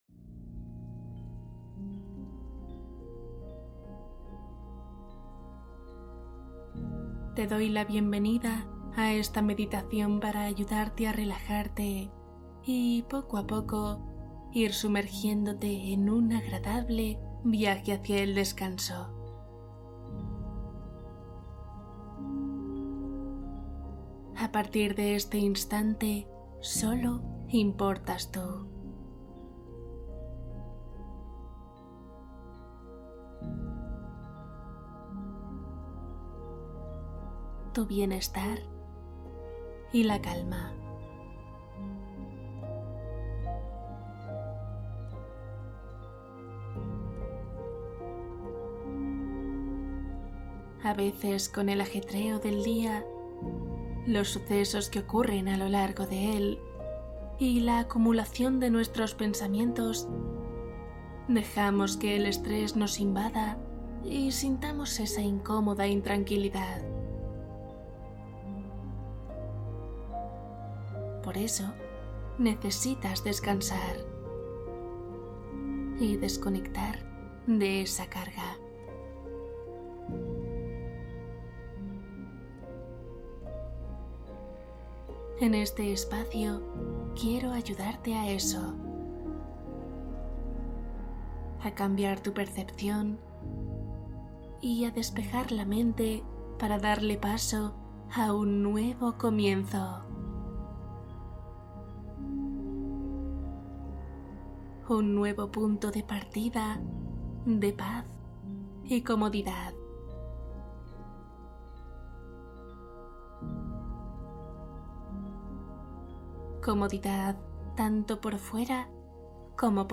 Cuento para un descanso profundo: meditación de una hora